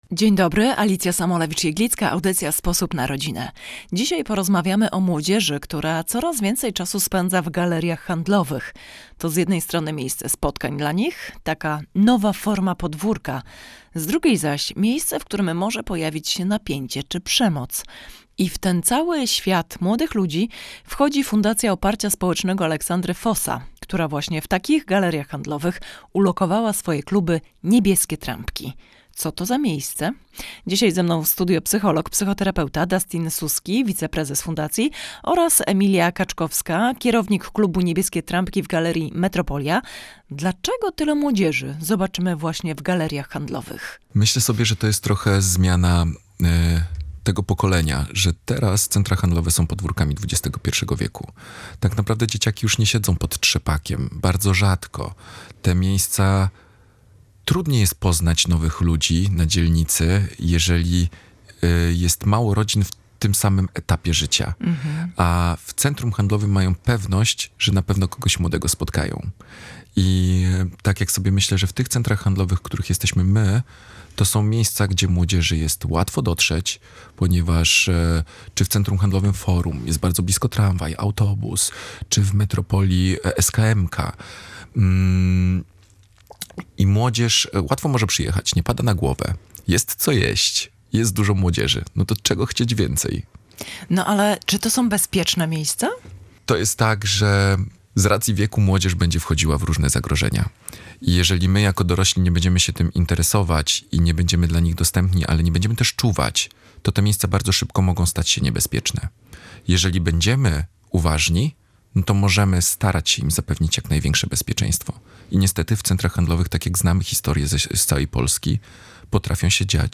W audycji „Sposób na rodzinę” rozmawialiśmy o młodzieży, która coraz więcej czasu spędza… w galeriach handlowych.